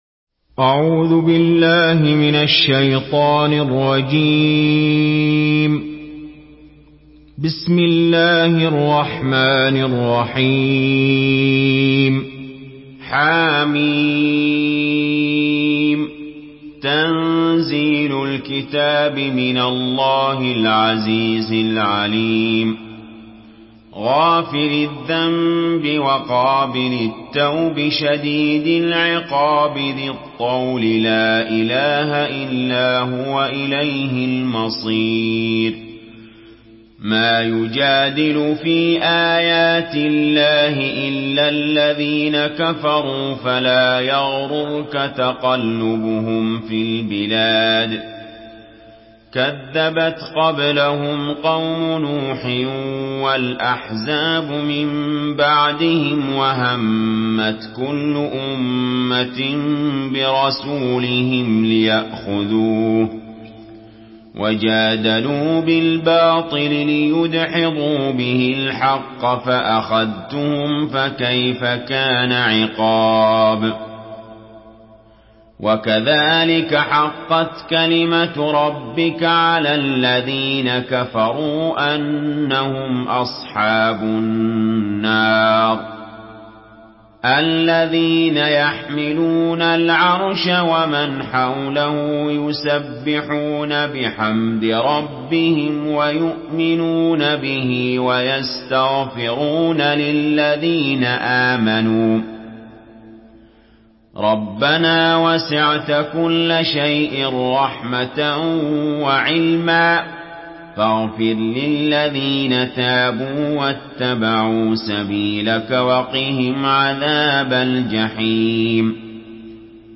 Surah Mümin MP3 by Ali Jaber in Hafs An Asim narration.
Murattal Hafs An Asim